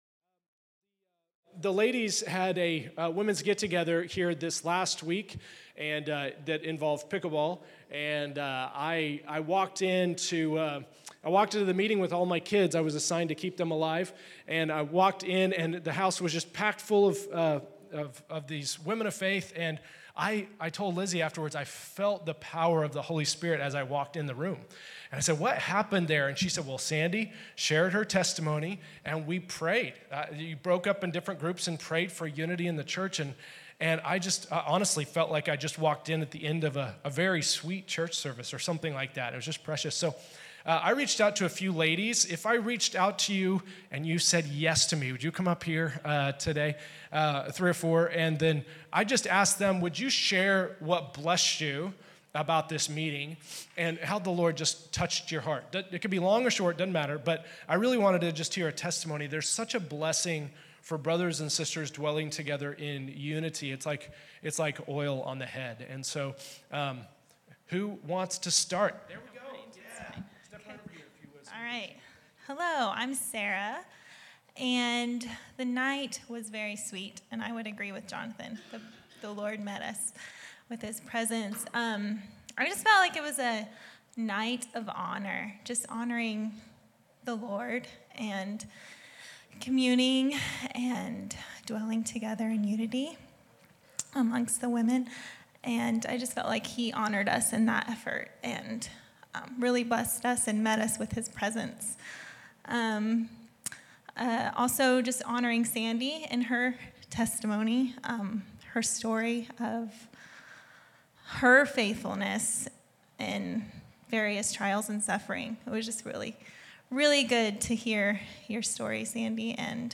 Women's Gathering - Report